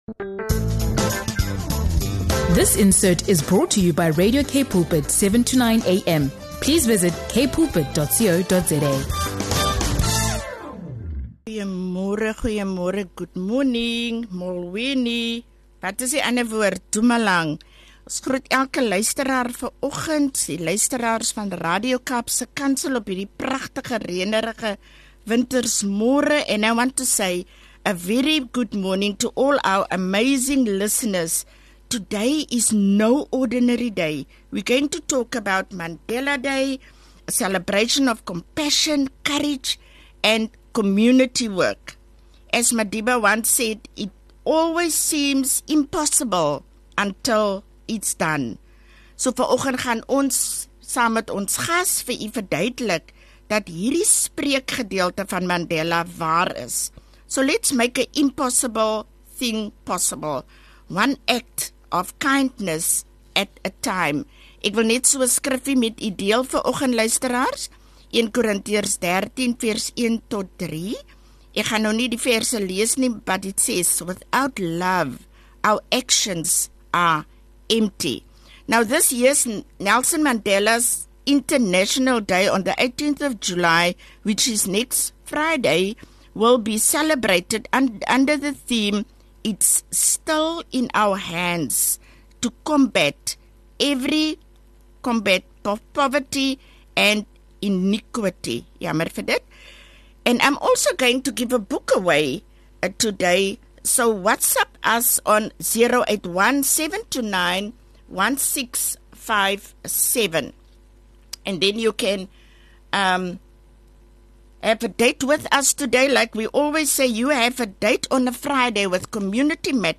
Tune in for an inspiring conversation on wisdom, resilience, and making a lasting difference in the community.